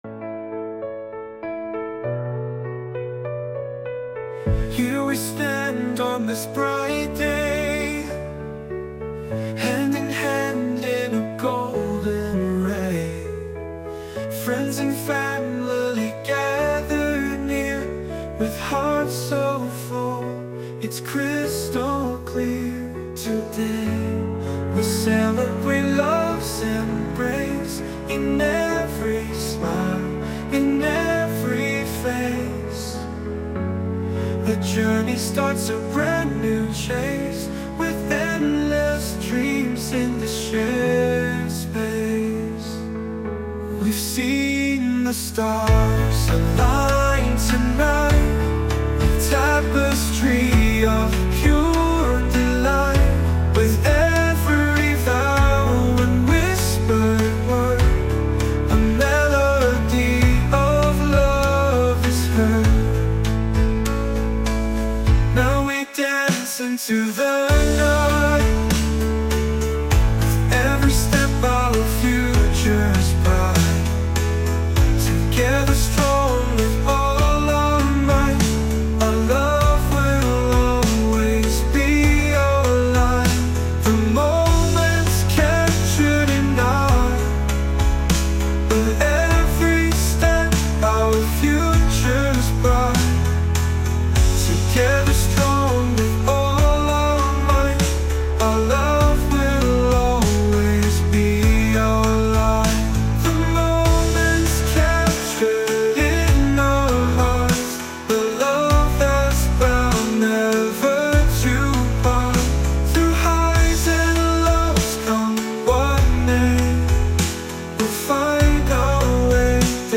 洋楽男性ボーカル著作権フリーBGM ボーカル
男性ボーカル洋楽 男性ボーカルプロフィールムービーエンドロール